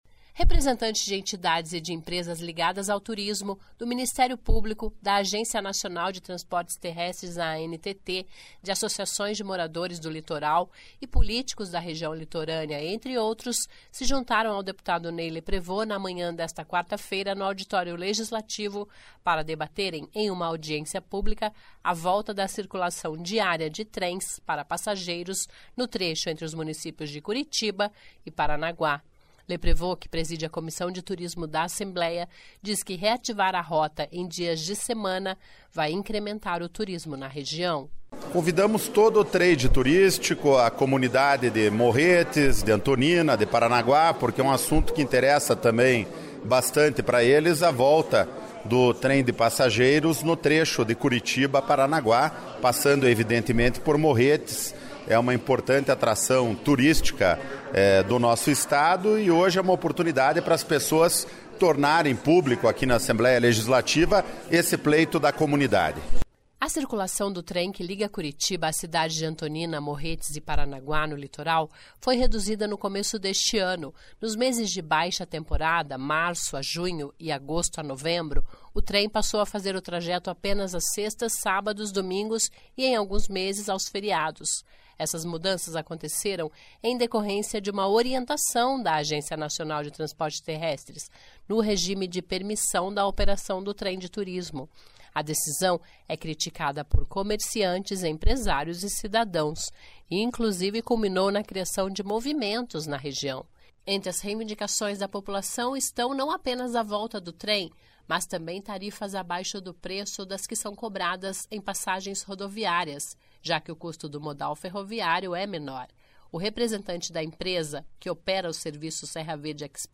Volta de viagens diárias de trem entre Curitiba e Paranaguá provoca debates em audiência